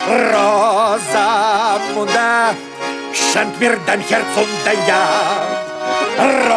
Из какого фильма этот аудиофрагмент?